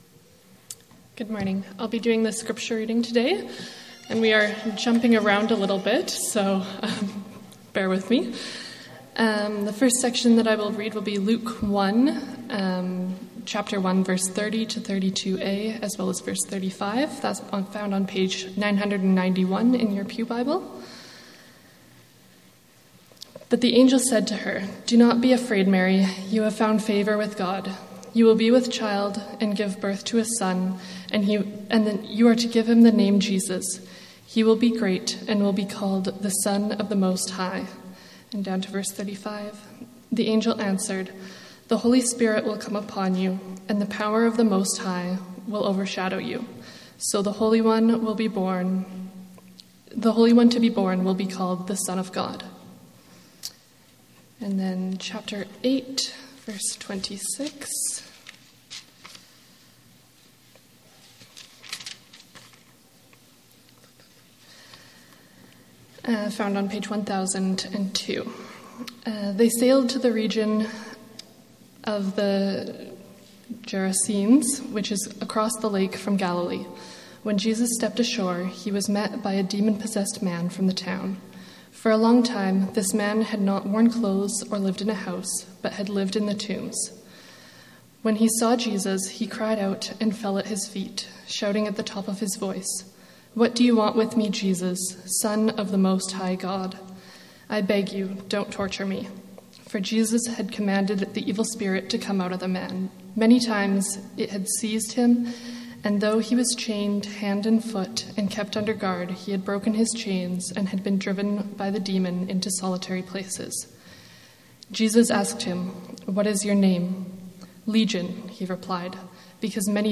پادکست های Bethesda Sermon Audio